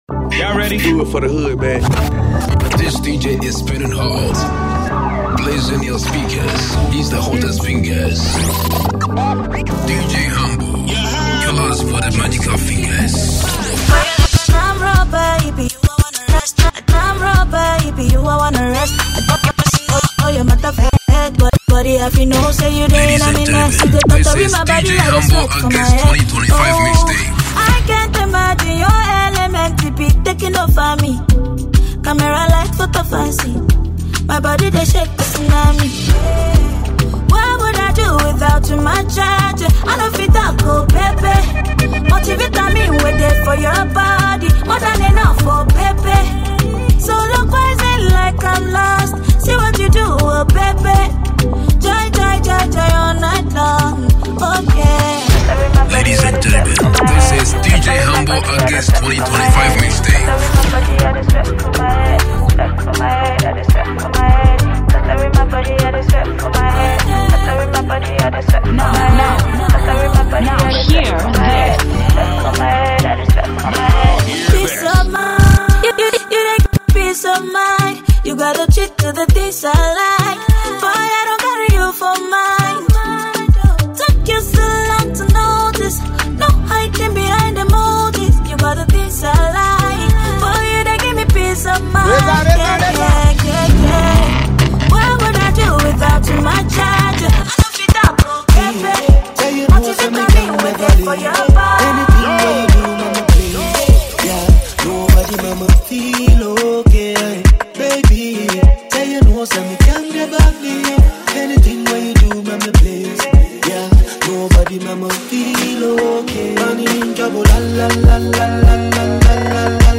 ” packed with hot Ghana music and Afrobeats hits.